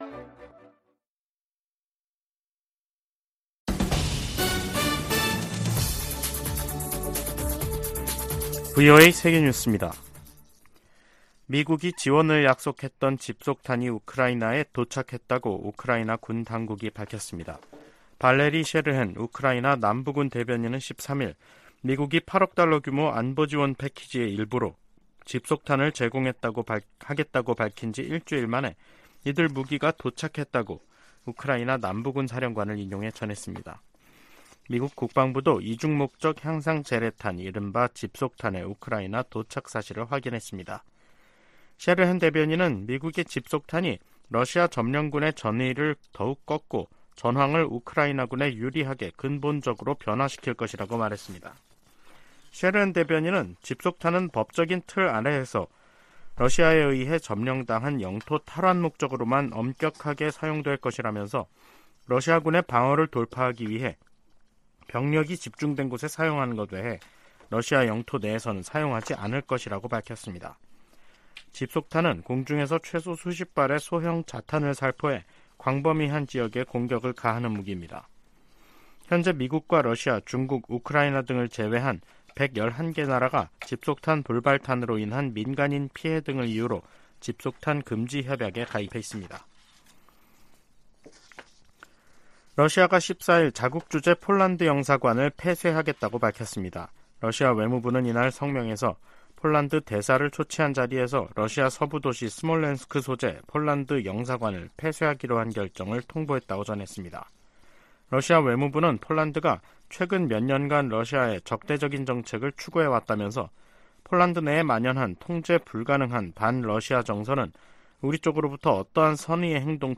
VOA 한국어 간판 뉴스 프로그램 '뉴스 투데이', 2023년 7월 14일 3부 방송입니다. 미한일과 유럽 나라들이 북한의 대륙간탄도미사일(ICBM) 발사 문제를 논의한 유엔 안보리 회의에서 북한을 강력 규탄하며 안보리의 단합된 대응을 거듭 촉구했습니다. 김정은 북한 국무위원장이 할 수 있는 최선의 방안은 대화 복귀라고 미 백악관이 강조했습니다. 한국 정부가 북한의 ICBM 발사에 대응해 정경택 인민군 총정치국장 등 개인 4명과 기관 3곳을 제재했습니다.